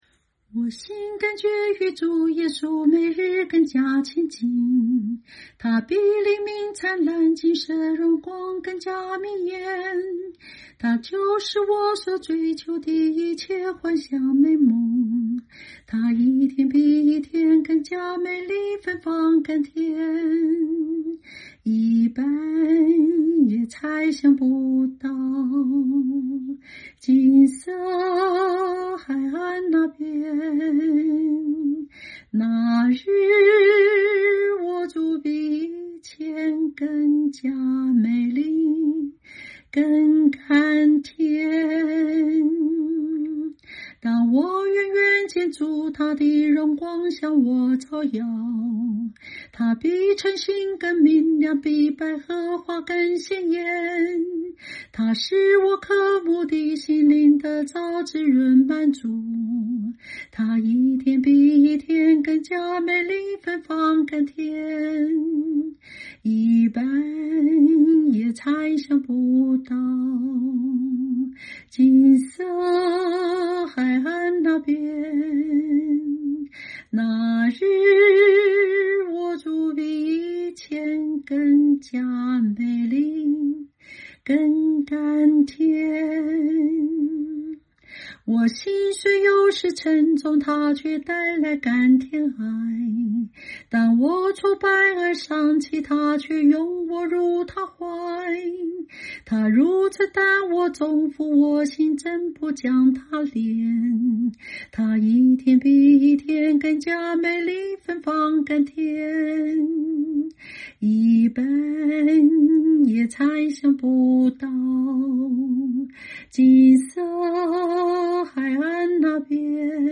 清唱    伴奏